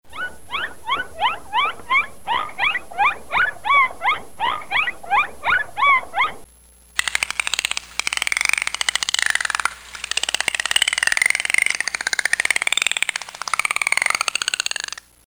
Bottlenose dolphin
Dolphins use sound to communicate with other dolphins, navigate through the water, watch for predators and find prey. Sounds include clicking, creaking, squeaking and whistling.
Puffing and hissing sounds can be heard when they exhale through their blowholes.
bottlenose-dolphin-call.mp3